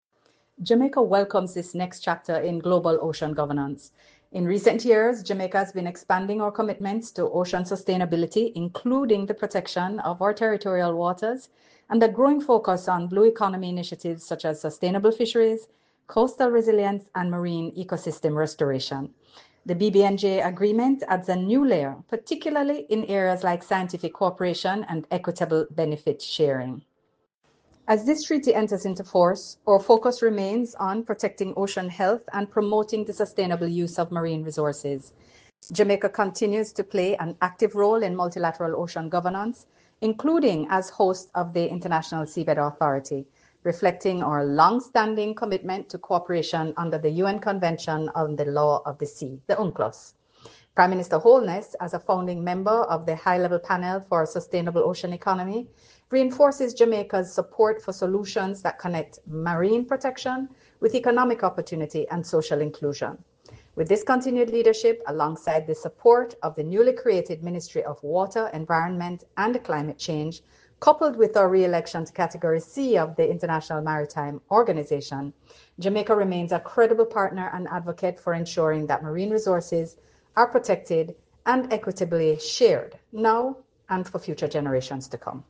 Minister_Johnson_Smith_Speaks_BBNJ.mp3